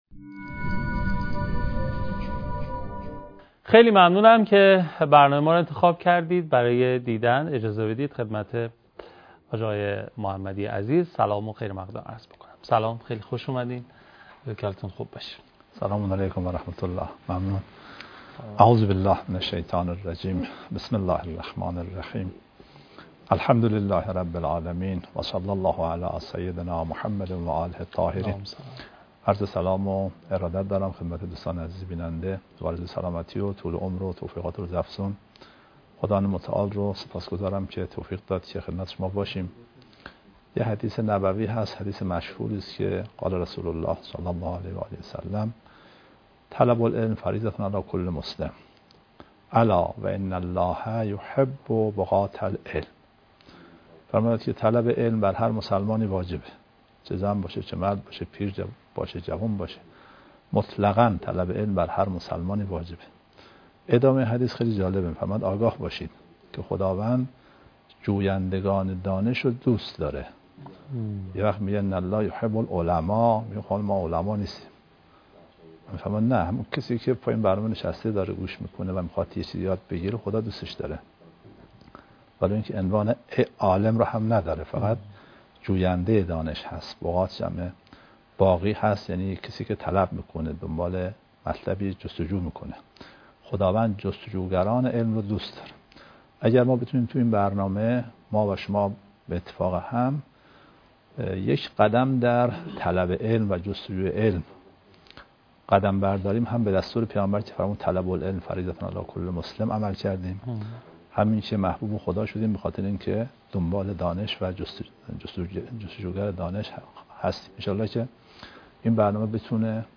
برنامه «پرسمان» مدتی است که در راستای نیاز مخاطبان و پاسخگویی به سؤالات دینی با حضور کارشناسان مطرح دینی و قرآنی به روی آنتن شبکه قرآن و معارف سیما می‌رود.